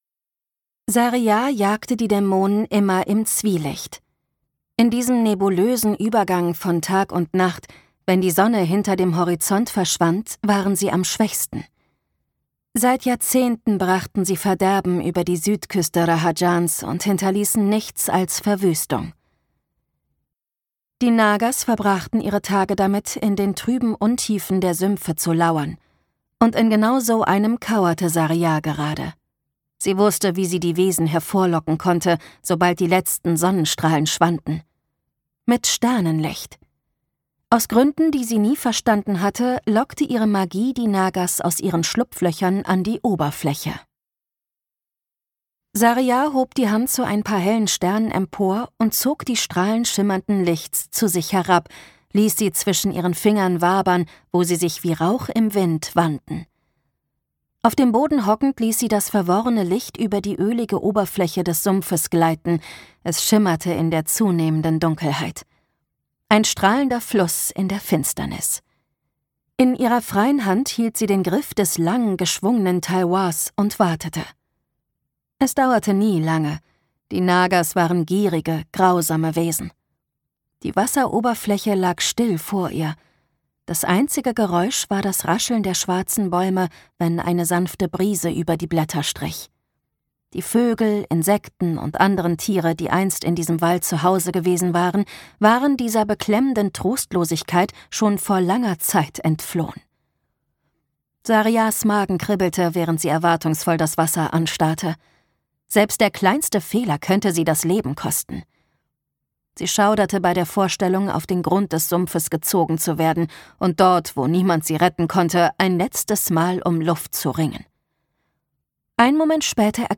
Heart of Night and Fire - Nisha J. Tuli | argon hörbuch
Gekürzt Autorisierte, d.h. von Autor:innen und / oder Verlagen freigegebene, bearbeitete Fassung.